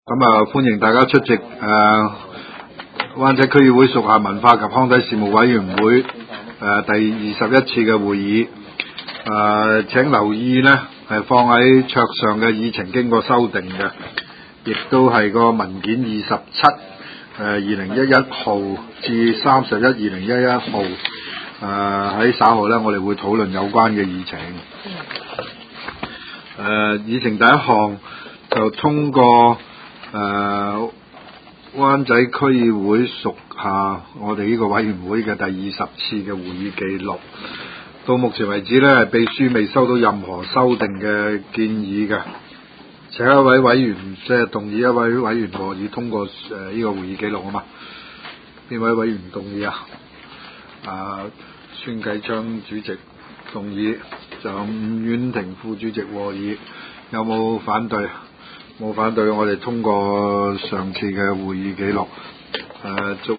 文化及康體事務委員會第二十一次會議
灣仔民政事務處區議會會議室